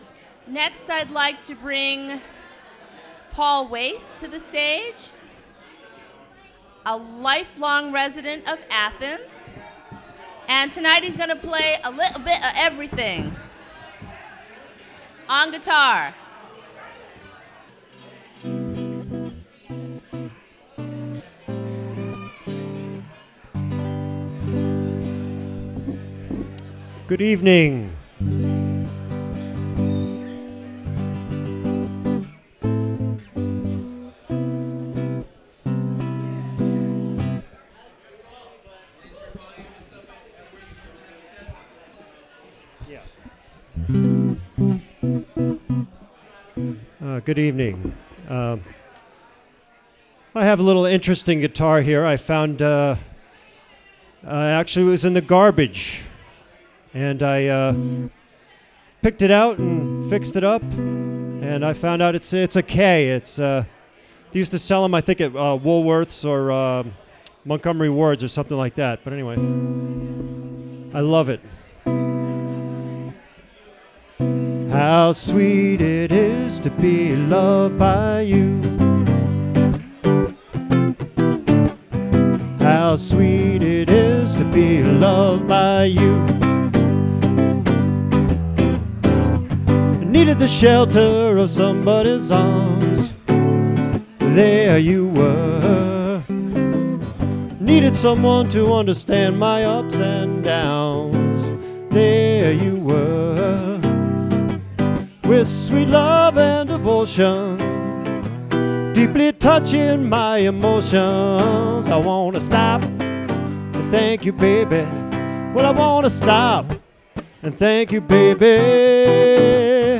Recorded from WGXC 90.7-FM webstream.